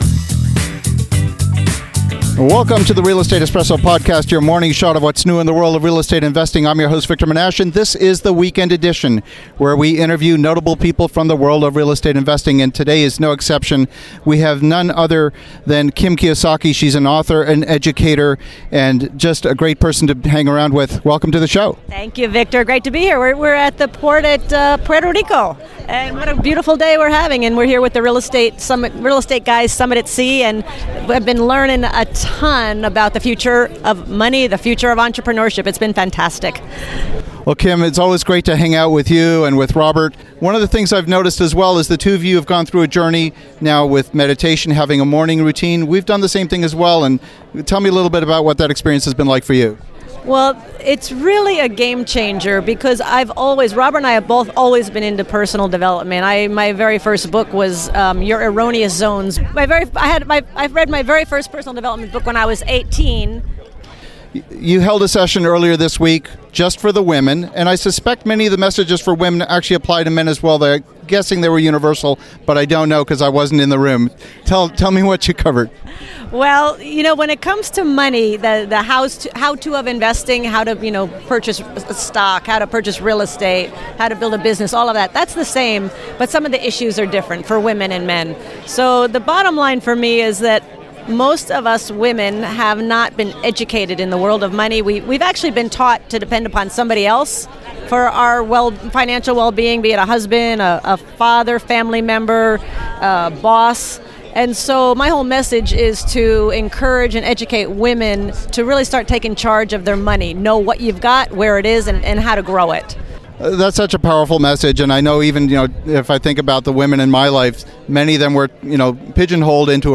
In this brief interview with Kim Kiyosaki in the Port of Puerto Rico, I got a surprise lesson. The conversation connected the dots on what I can only describe as a blind spot.